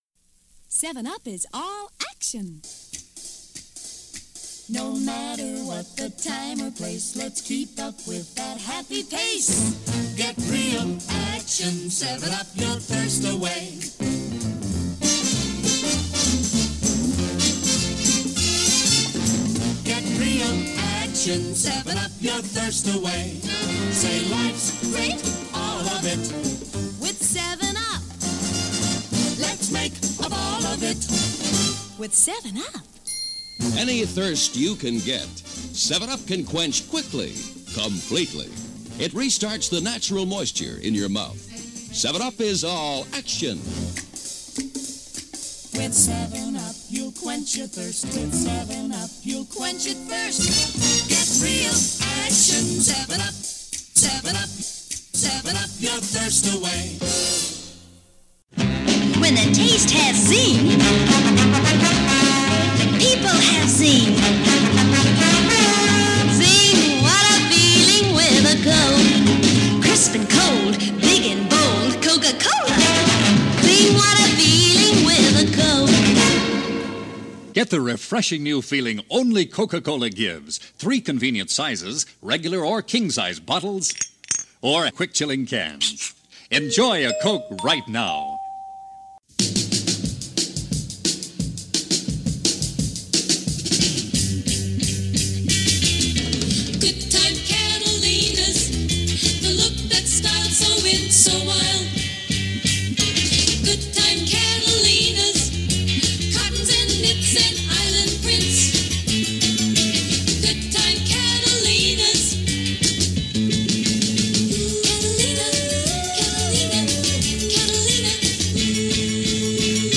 Days Of Conspicuous Consumption - Radio Commercials In The 1960's: Youthquake - Past Daily Pop Chronicles